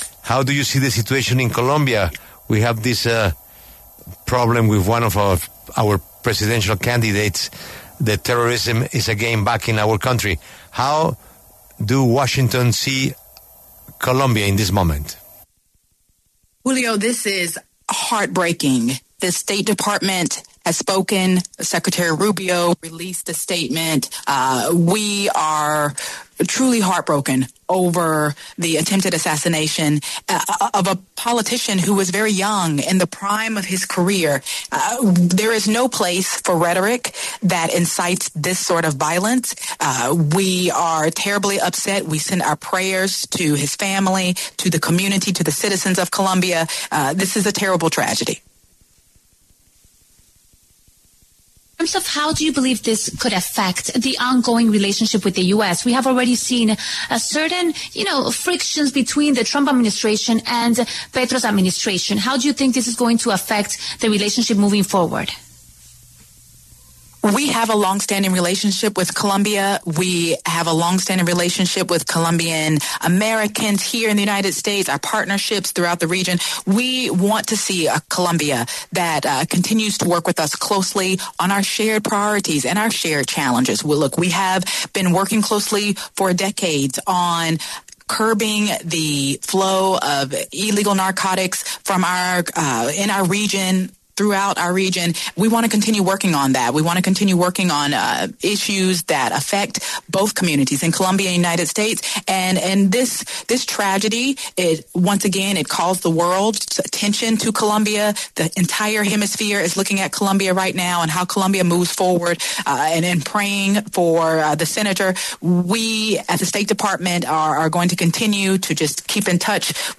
Mignon Houston, portavoz adjunta del Departamento de Estado de EE.UU. habló en La W